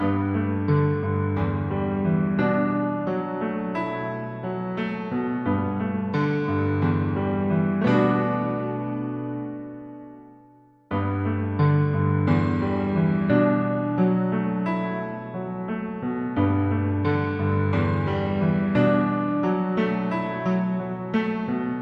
又是钢琴 88 G
标签： 88 bpm RnB Loops Piano Loops 3.68 MB wav Key : G
声道立体声